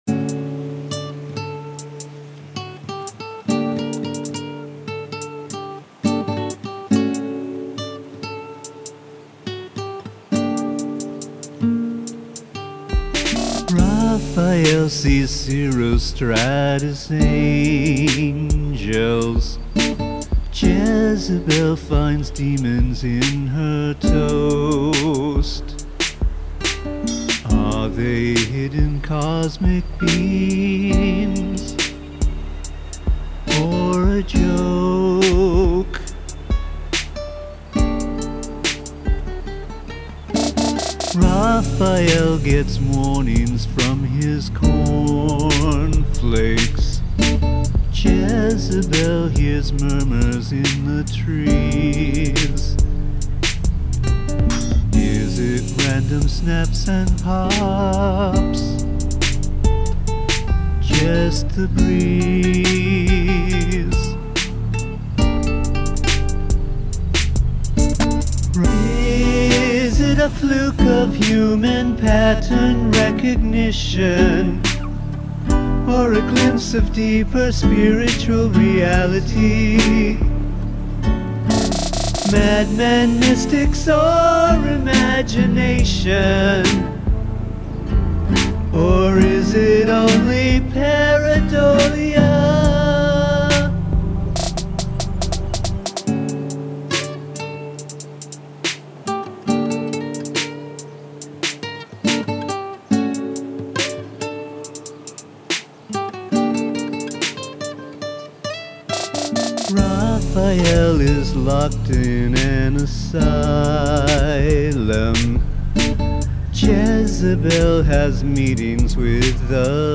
Use of field recording